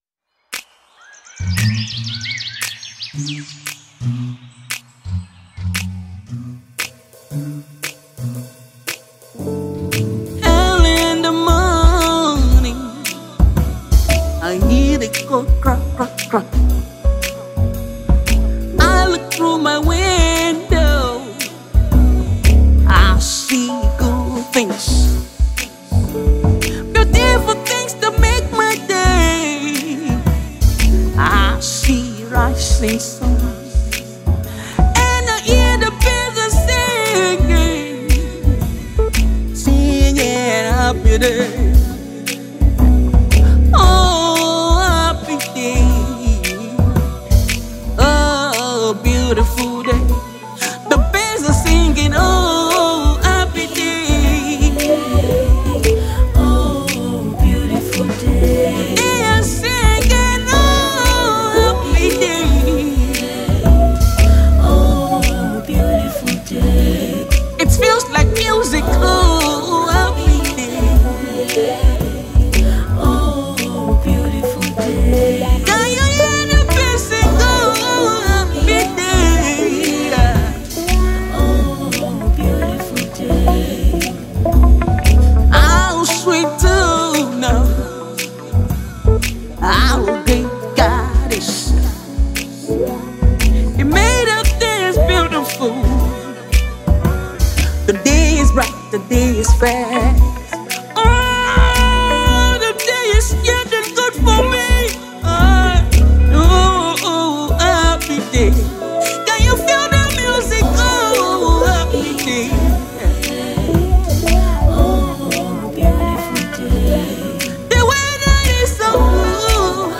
Nigerian talented gospel singer and songwriter